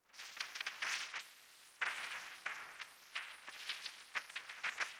stochastic synthesis violin sound effect free sound royalty free Memes